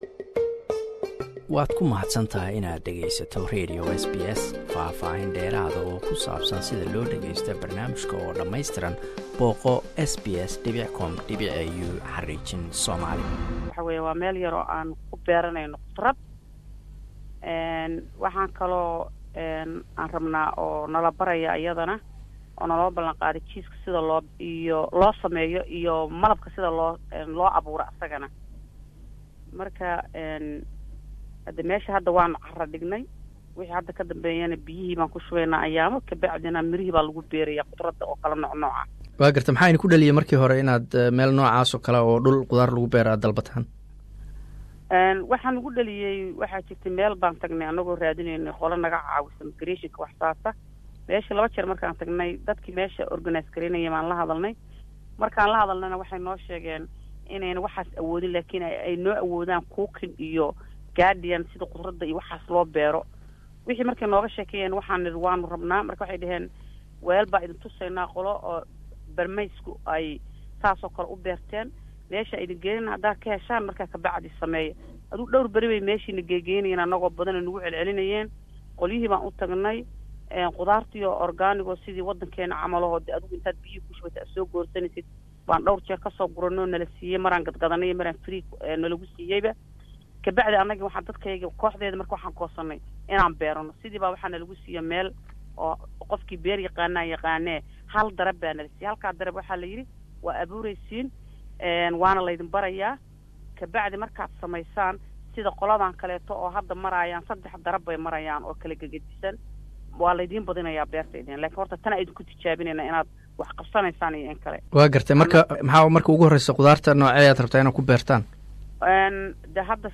Qaar ka mid ah haweenka Soomalida degan Melbourne ayaa la siiyay dhul yar oo ay ku beertaan qudaar. Waxaan ka waraysanay